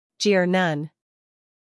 英音/ dʒɪə(r) / 美音/ dʒɪr /